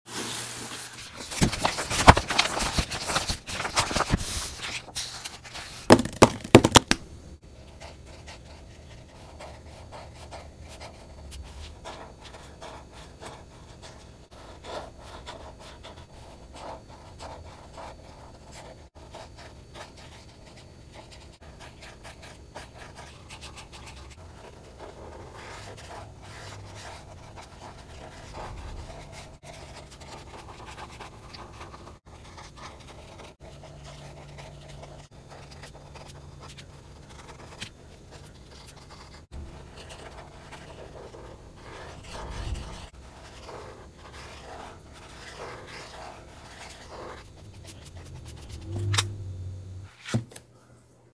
Cozy fall coloring! 😊🍂🍁 ASMR sound effects free download